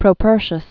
(prō-pûrshəs, -shē-əs), Sextus 50?-15? BC.